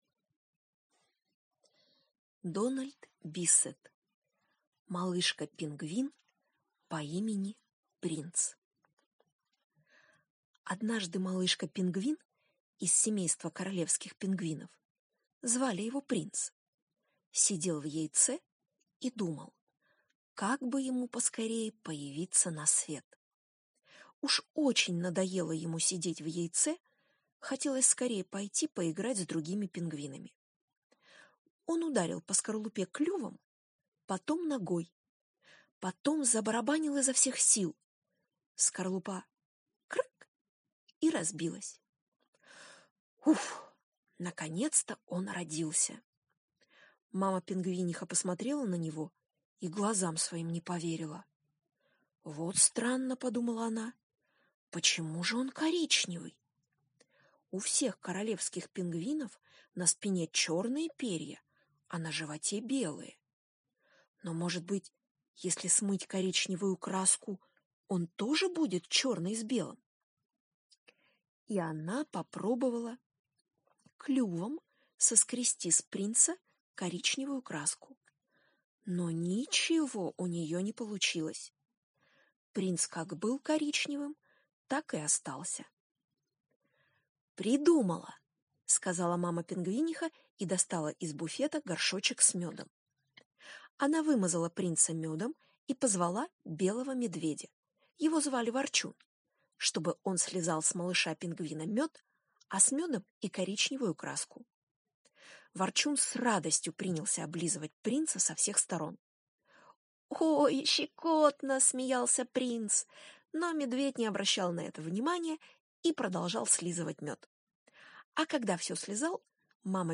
Аудиосказка «Малышка пингвин по имени Принц»